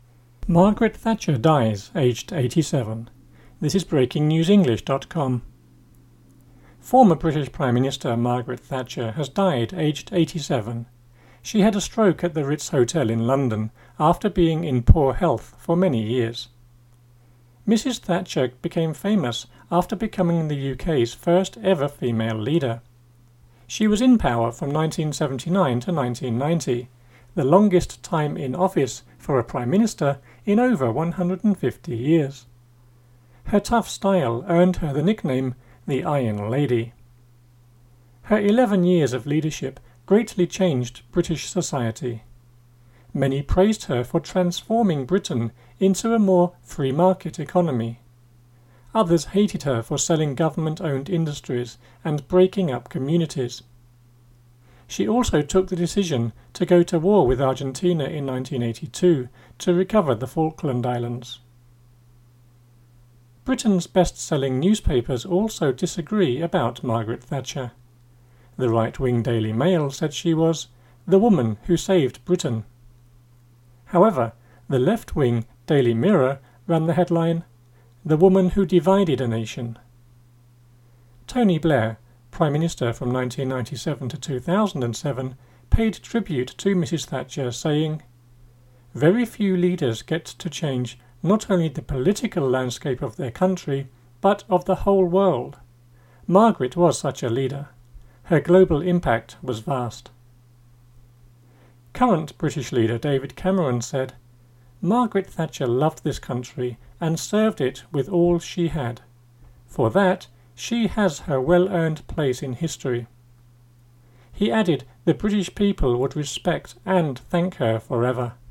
MP3 (UK male)